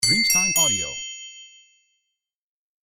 Carillon 0001 di multimedia di notifica del messaggio
• SFX